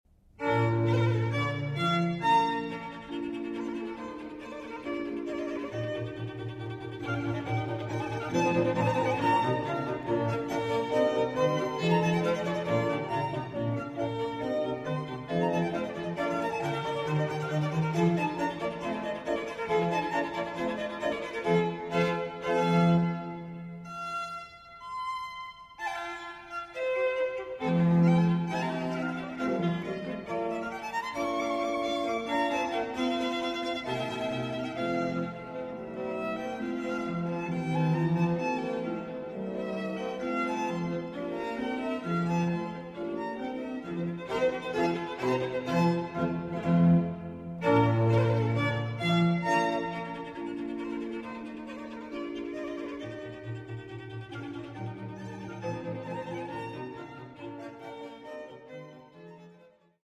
B flat major